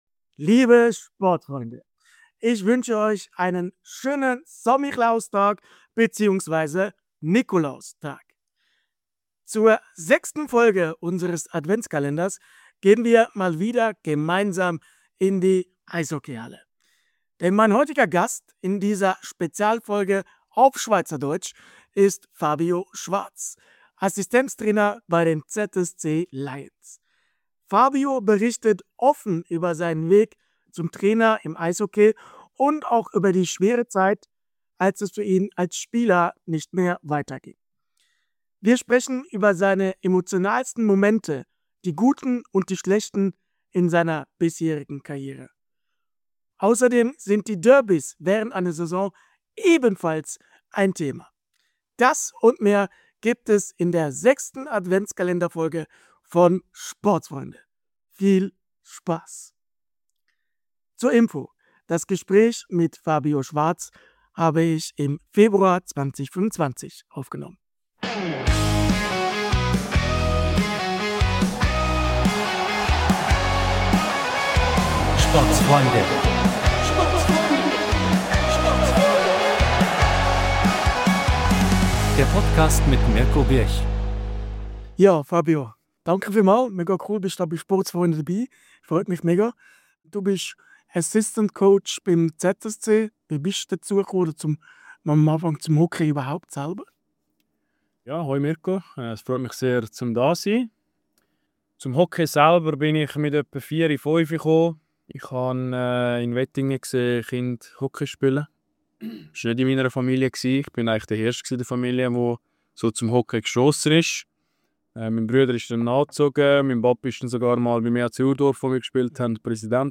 ADVENTSKALENDERFOLGE 6 bei SPORTSFREUNDE!
Spezialfolge auf Schweizerdeutsch